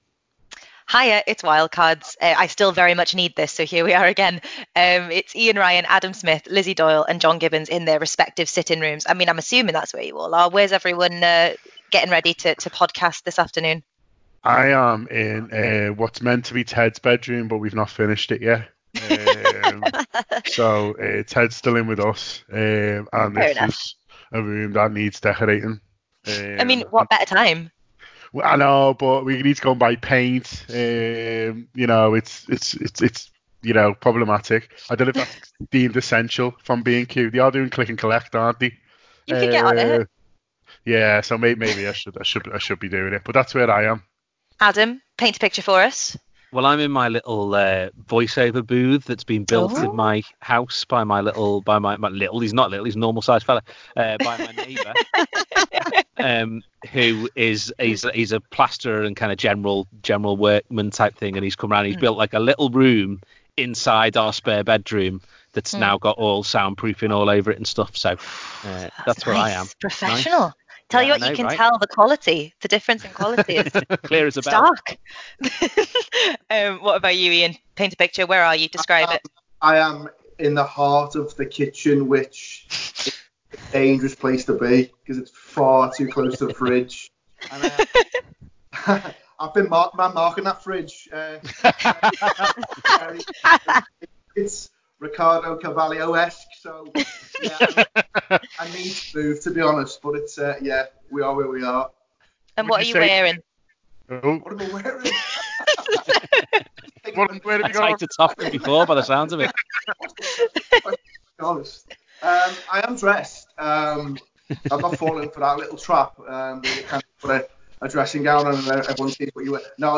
On this week's Wildcards the panel discusses dream signings, biggest signing we missed out on, who should win PFA Player of the Year and Greggs?